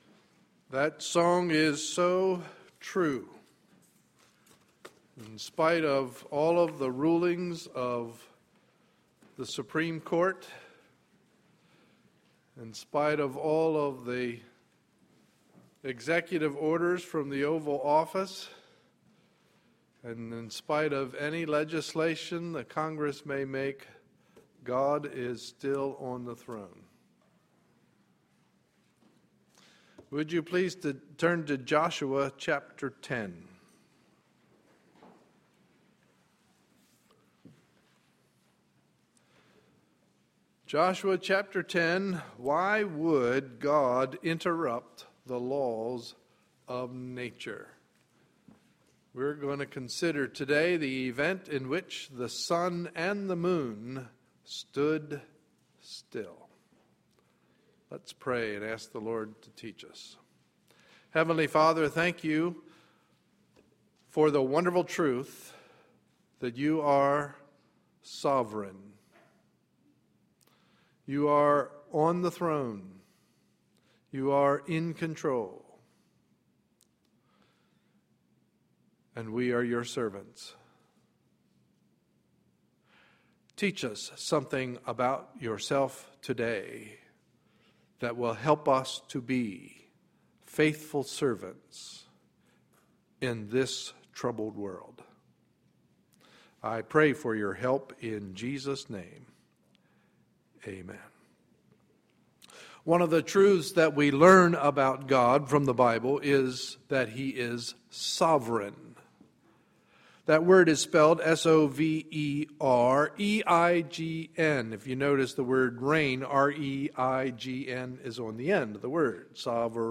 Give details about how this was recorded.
Sunday, June 30, 2013 – Morning Service